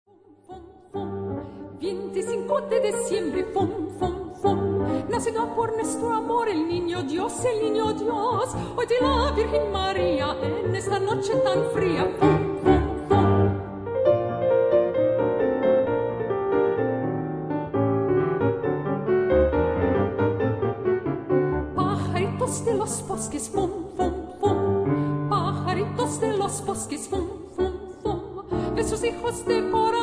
Catalan Christmas Carol
mezzo-soprano
pianist